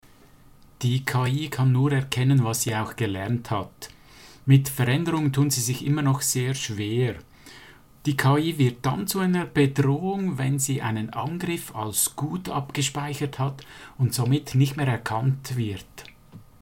Dieses Interview gibt es auch auf Schwitzerdütsch!